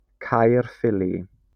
Caerphilly (/kəˈfɪli/, /kɑːrˈfɪli/;[2] Welsh: Caerffili, Welsh pronunciation: [ˌkairˈfɪlɪ]